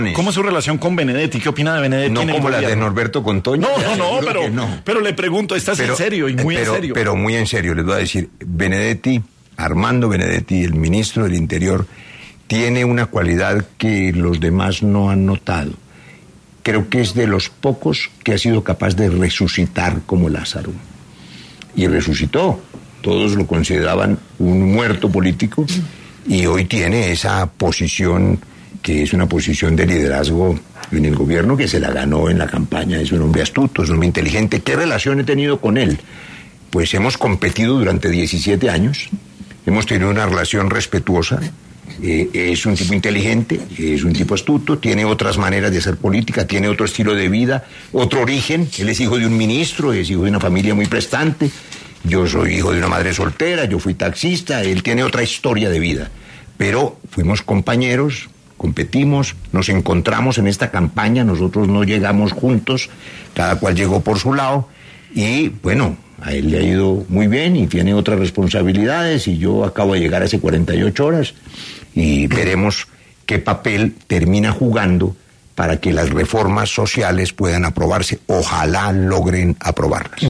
Tras su regreso a Colombia, luego de desempeñarse como el embajador de Colombia en el Reino Unido e Irlanda del Norte, Roy Barreras ofreció declaraciones en los micrófonos de ‘Sin Anestesia’, de ‘La Luciérnaga’.